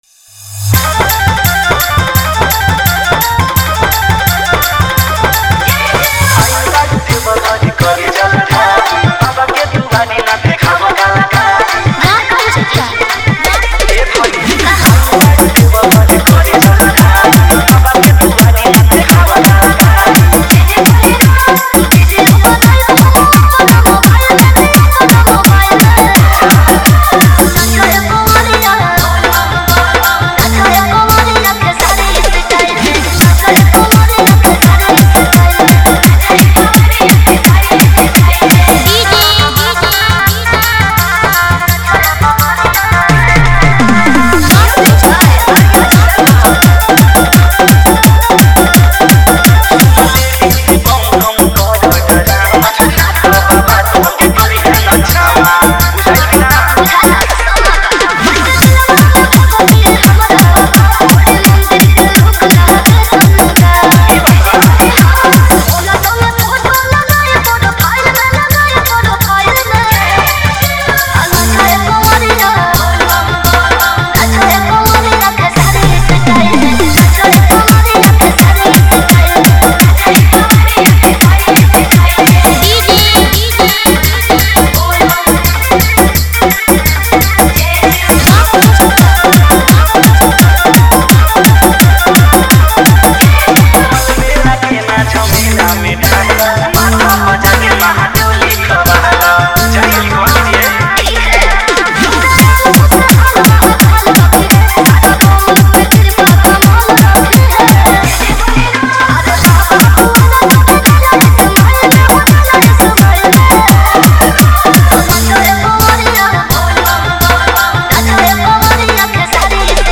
Category:  Bol Bam 2023 Dj Remix Songs